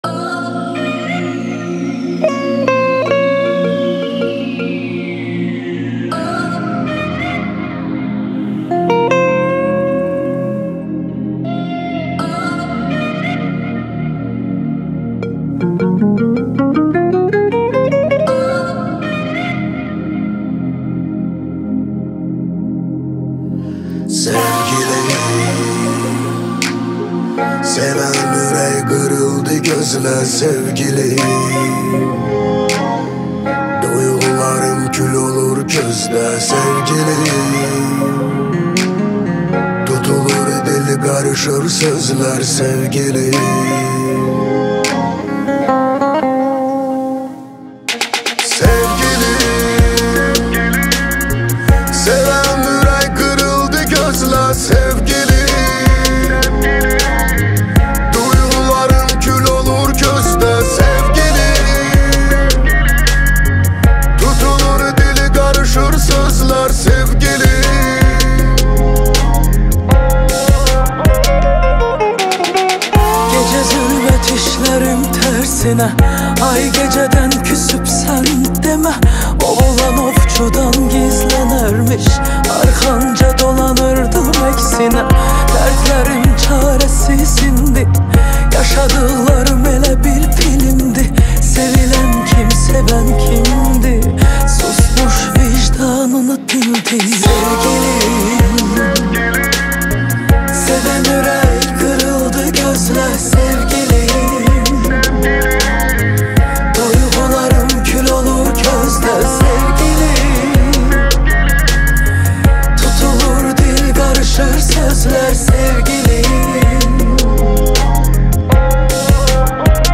известная своим ярким вокалом и эмоциональной подачей